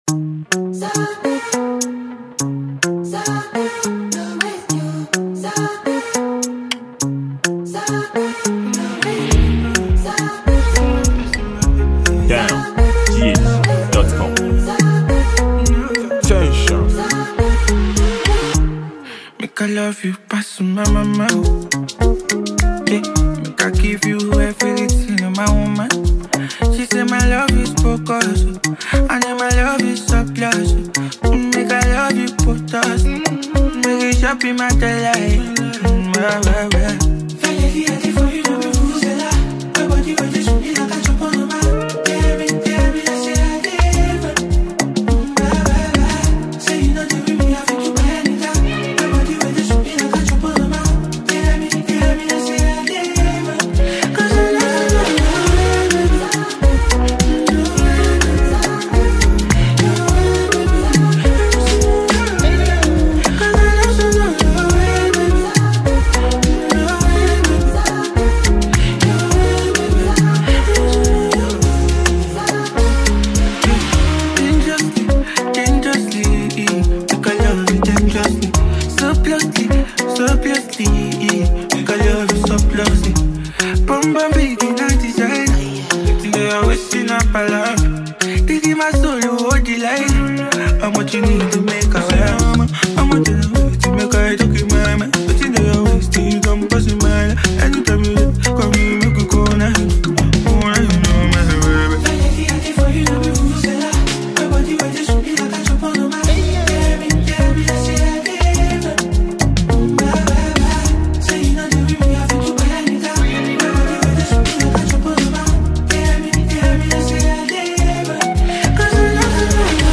a free mp3 love tune to the fans and lovers.
This is a blend of Afrobeat and Nigerian Highlife.